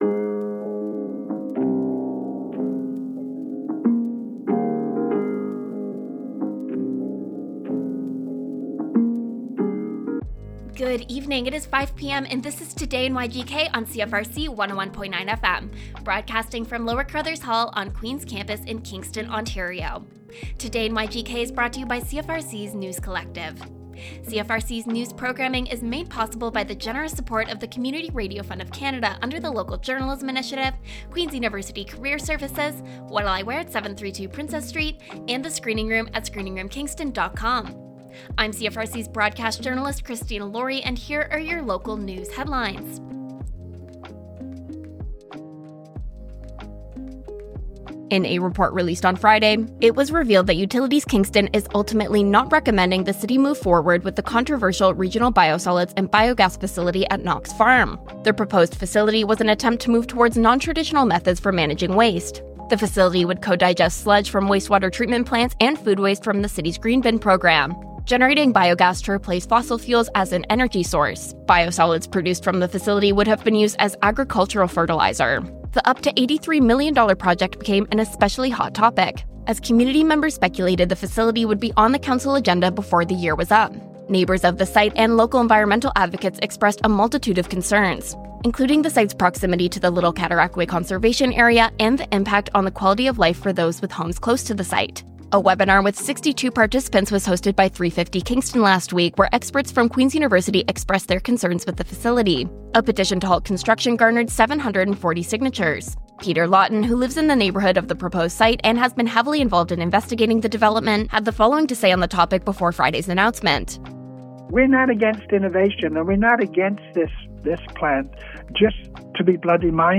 Local news updates with CFRC’s News Team.